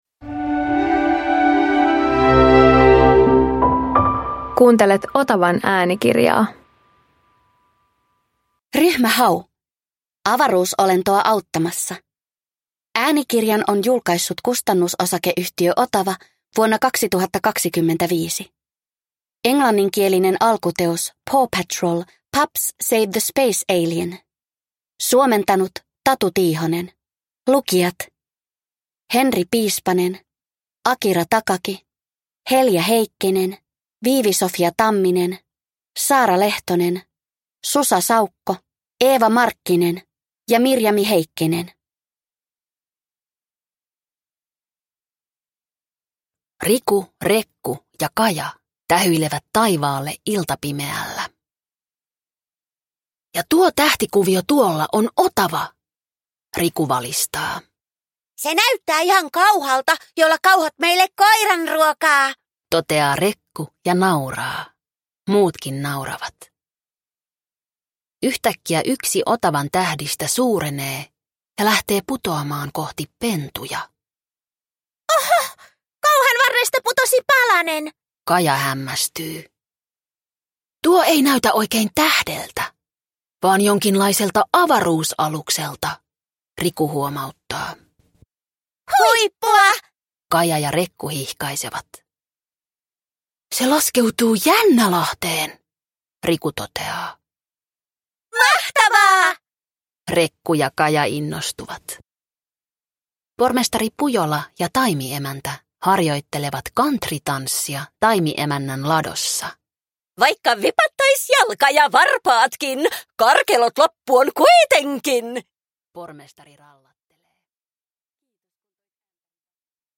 Ryhmä Hau - Avaruusolentoa auttamassa – Ljudbok